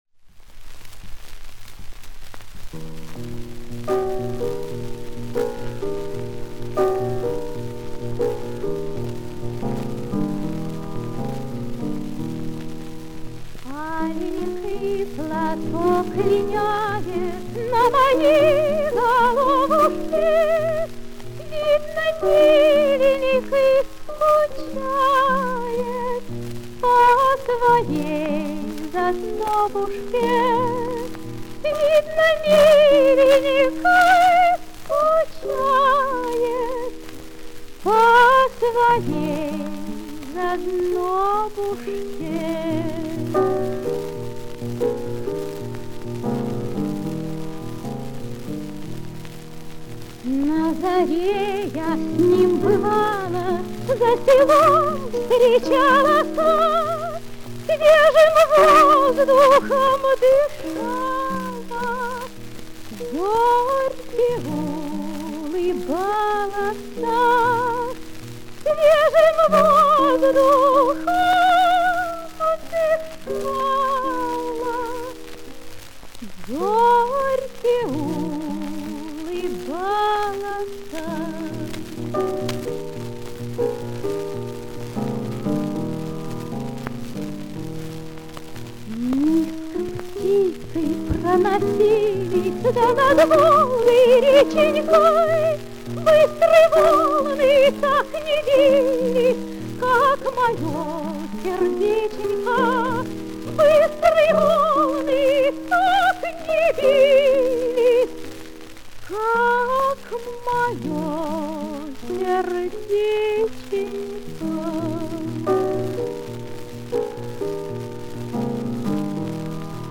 Каталожная категория: Сопрано с фортепиано |
Жанр: Народная песня
Вид аккомпанемента: Фортепиано |
Место записи: Москва |